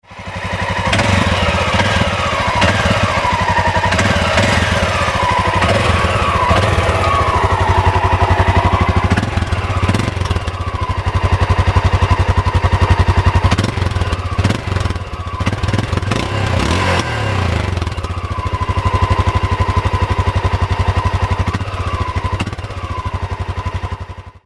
Klicka för att höra originalsystem